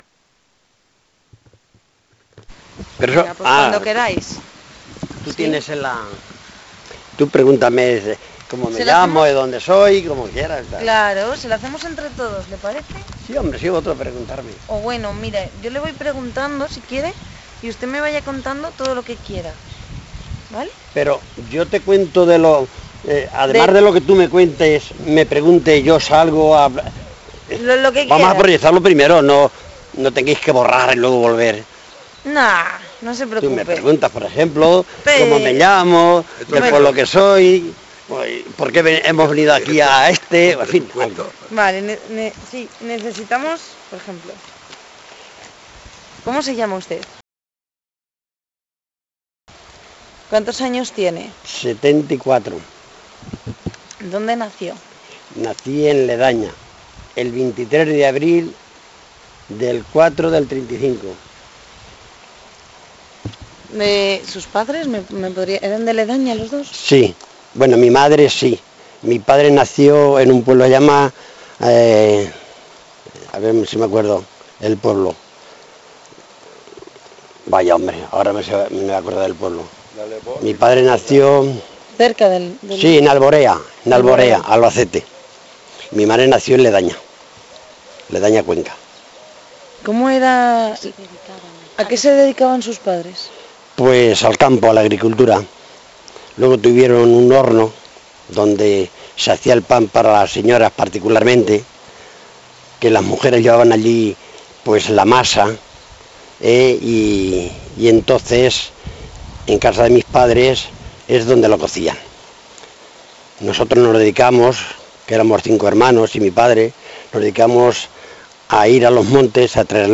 Encuesta